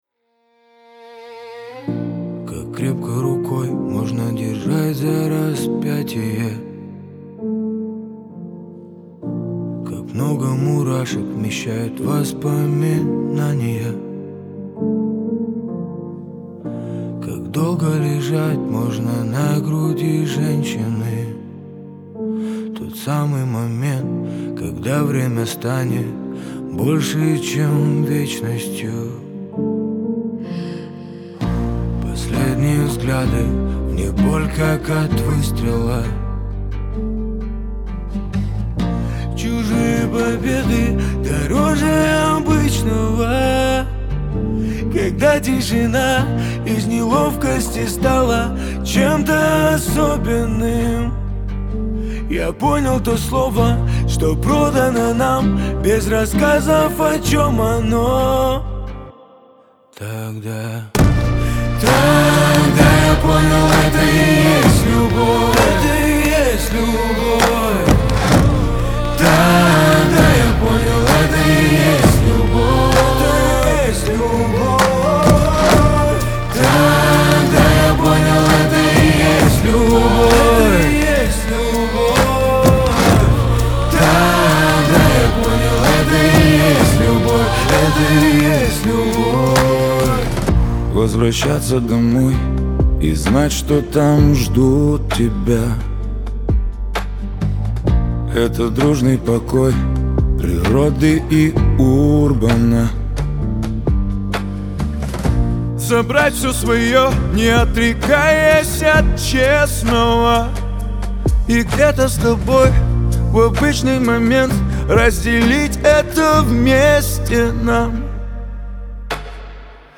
диско
дуэт , эстрада
dance , pop